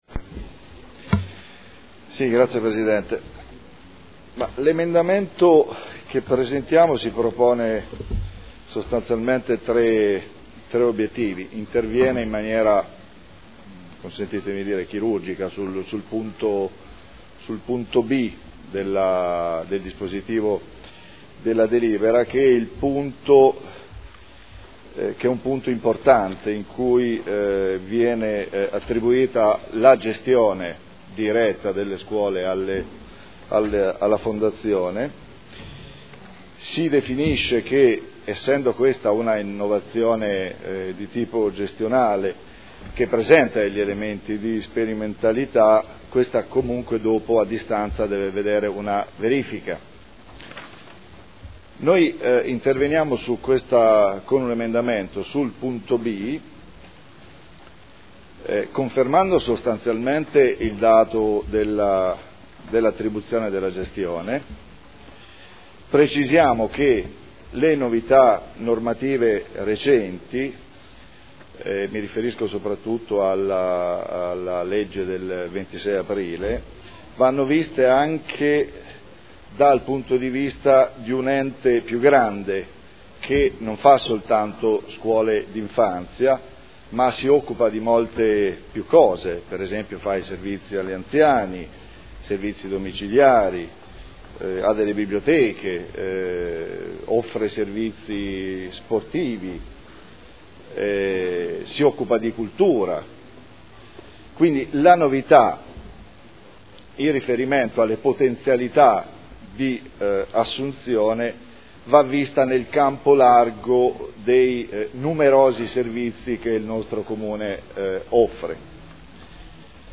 Seduta del 03/05/2012. Presenta emendamento a proposta di deliberazione: Linee di indirizzo per la costituzione di una Fondazione finalizzata a gestire servizi scolastici ed educativi comunali 0/6 anni